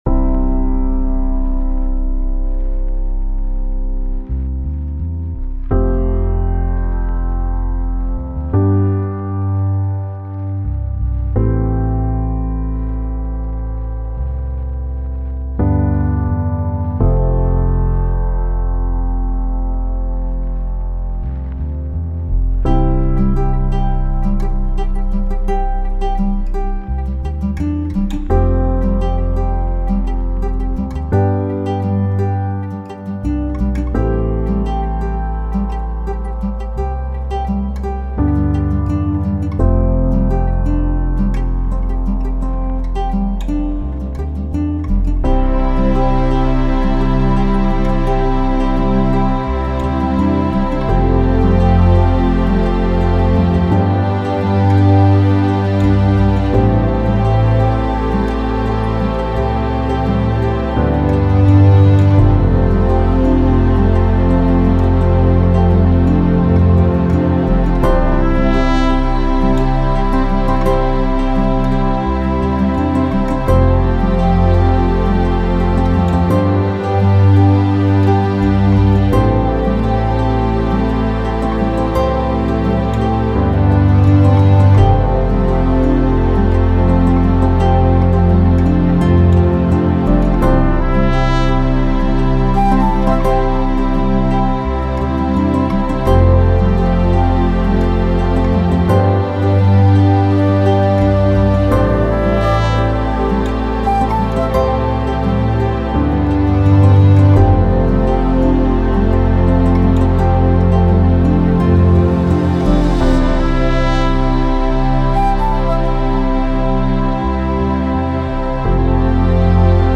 both-of-us-acoustic-version-14038.mp3